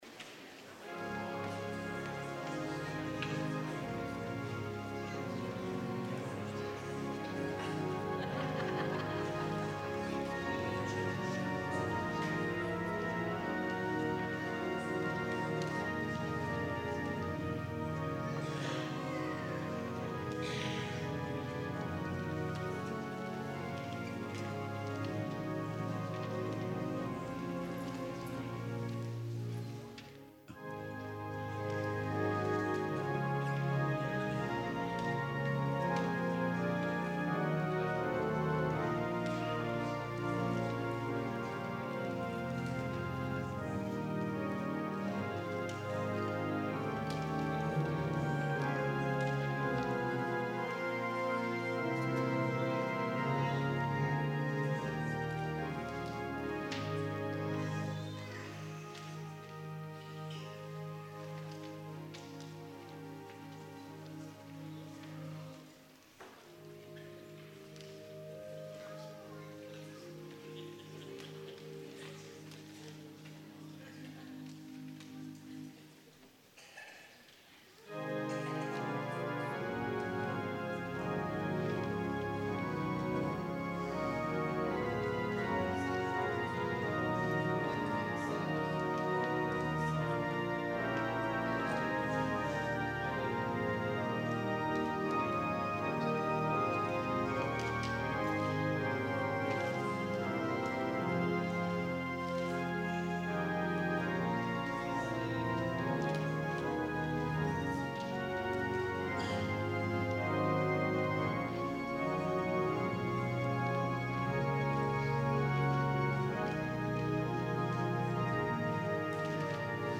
organ
guest organist